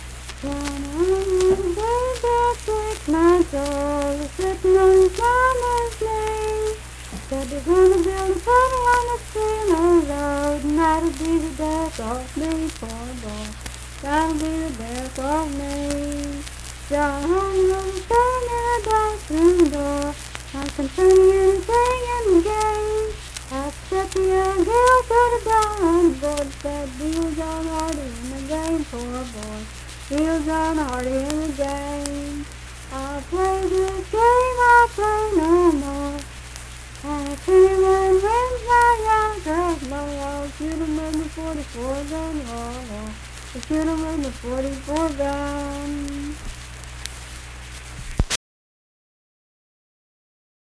Unaccompanied vocal music
Voice (sung)
Braxton County (W. Va.), Sutton (W. Va.)